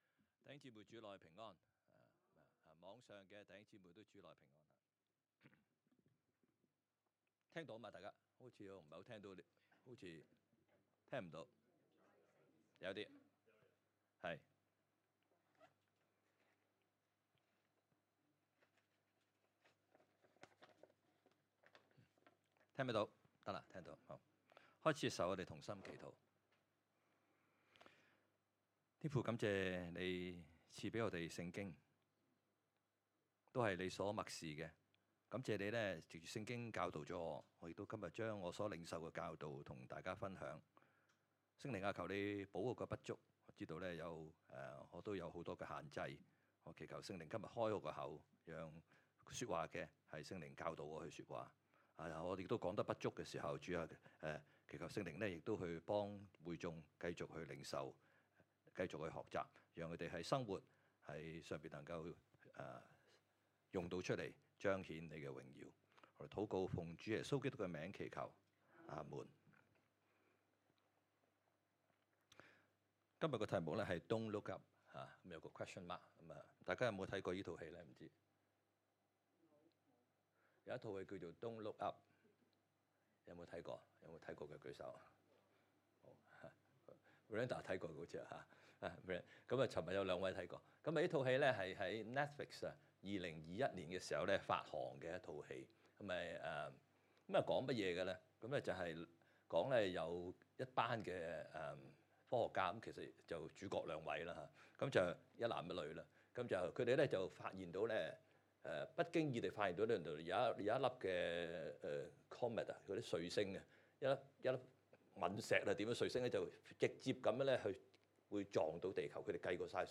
講道 : Don’t Look Up?